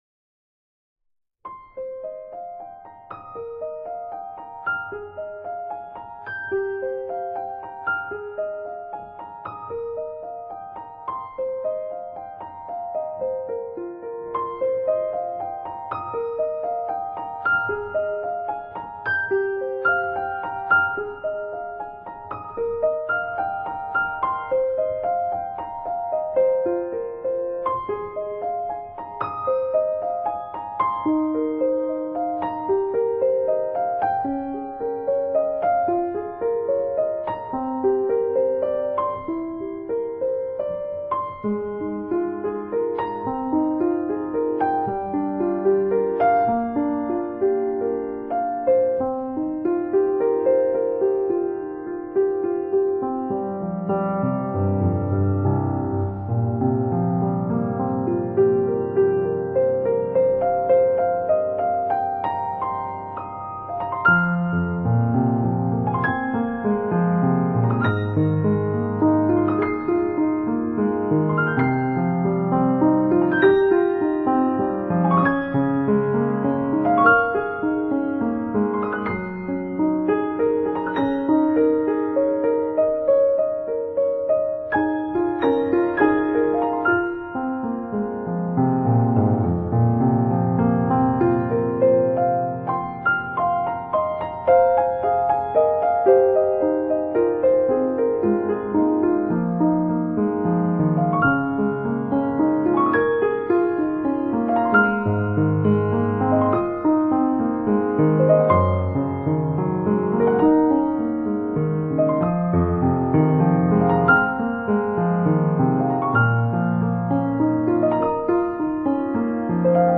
搖身一變成為新世紀鋼琴意象的代表。
清清澹澹的琴音獻給每一顆豐富的心靈。